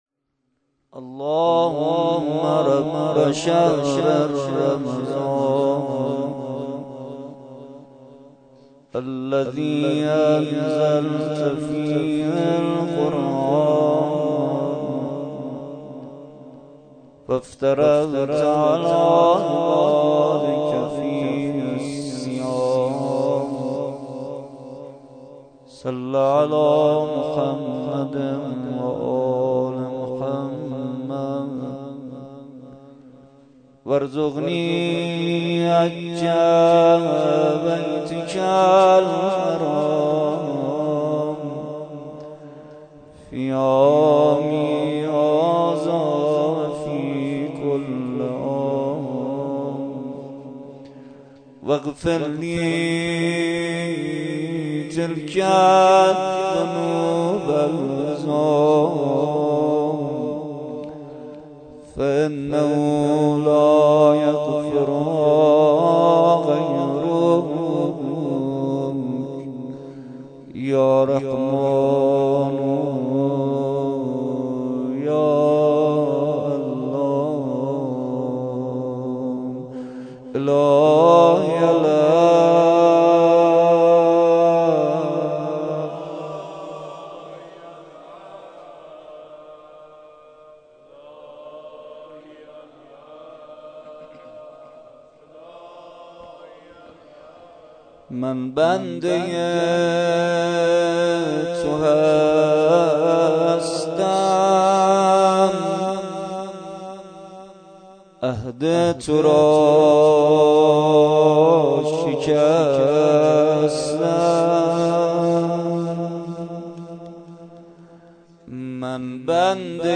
شب ششم ماه رمضان با مداحی کربلایی محمدحسین پویانفر در ولنجک – بلوار دانشجو – کهف الشهداء برگزار گردید.
کد خبر : ۵۶۵۷۲ عقیق:صوت این جلسه را بشنوید: دعا و مناجات روضه لینک کپی شد گزارش خطا پسندها 0 اشتراک گذاری فیسبوک سروش واتس‌اپ لینکدین توییتر تلگرام اشتراک گذاری فیسبوک سروش واتس‌اپ لینکدین توییتر تلگرام